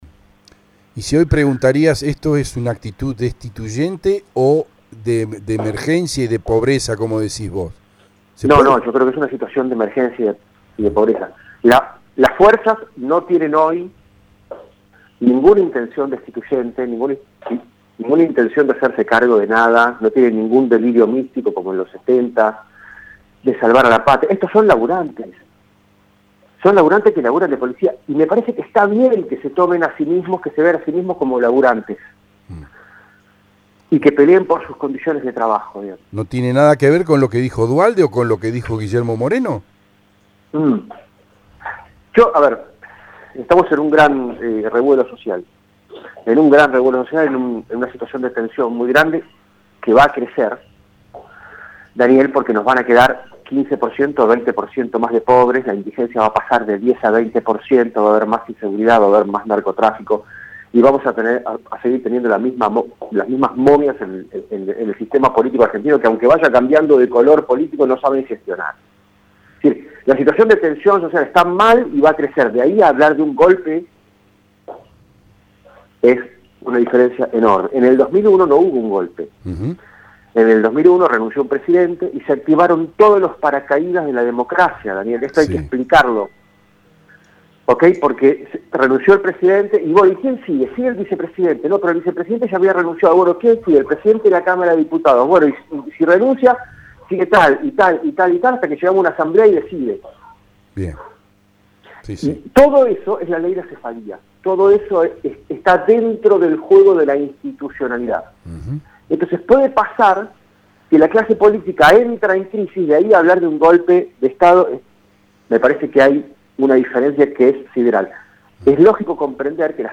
Y. al final de la entrevista, hablamos de la pandemia, del monotema de los últimos seis meses y de como midió la cuestión que raya los 180 días, en la opinión de la gente, de los 2500 que encuestaron.